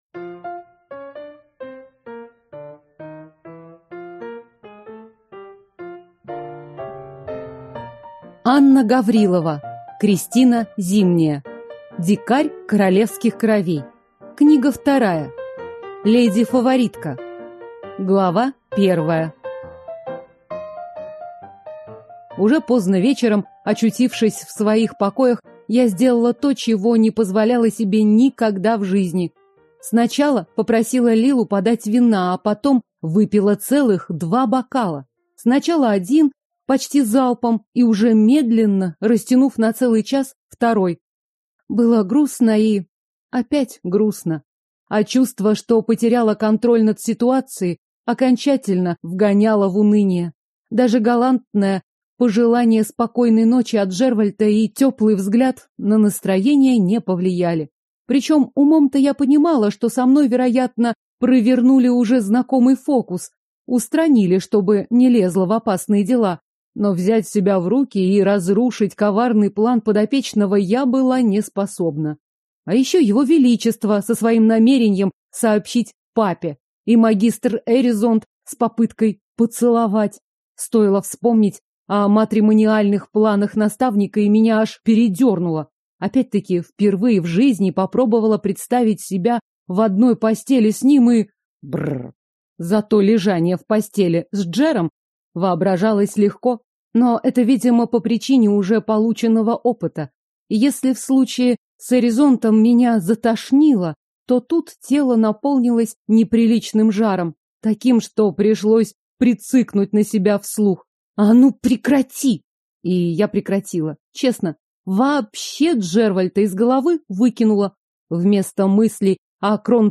Аудиокнига Дикарь королевских кровей. Книга 2. Леди-фаворитка | Библиотека аудиокниг